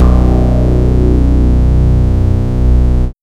TRANCE ACT-L.wav